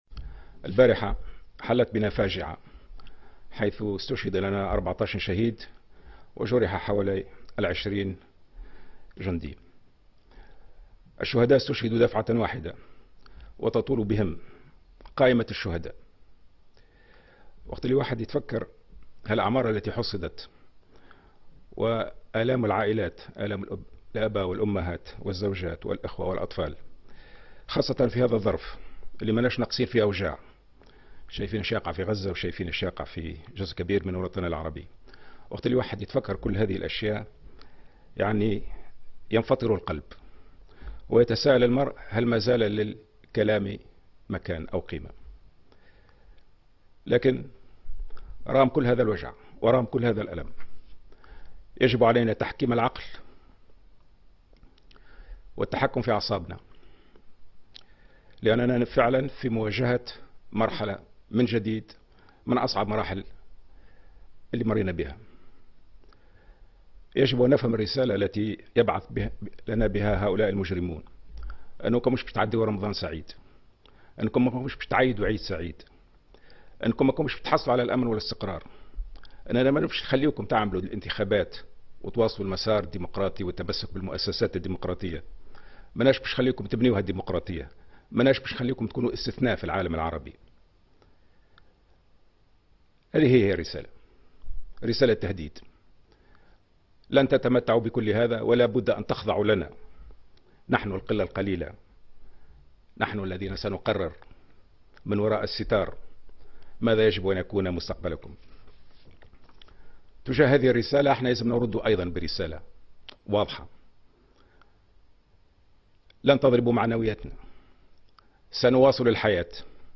قال رئيس الجمهورية محمد المنصف المرزوقي في كلمة توجه بها للشعب التونسي مساء اليوم الخميس إنه يجب تحكيم العقل والتحكم في الأعصاب لأن هذه المجموعات الإرهابية ترغب في ايصال رسالة تهديد للشعب التونسي مؤكدا أن تونس لن ترضخ لتهديداتهم .